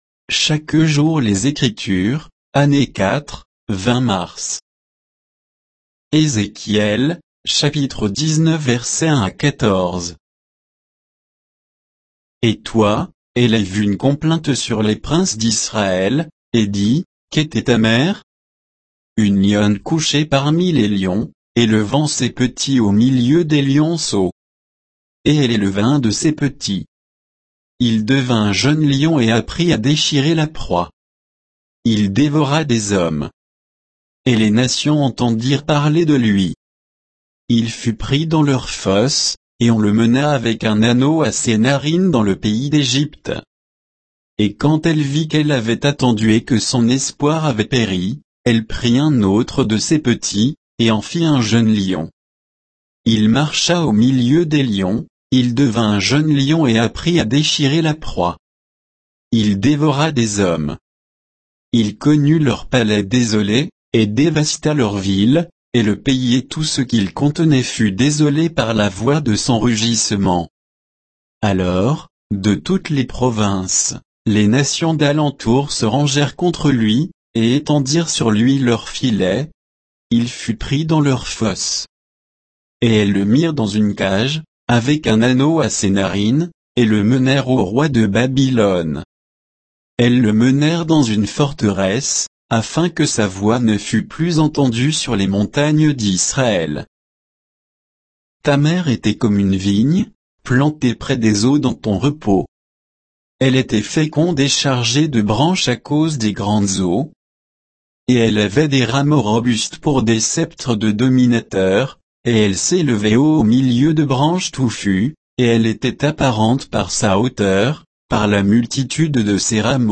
Méditation quoditienne de Chaque jour les Écritures sur Ézéchiel 19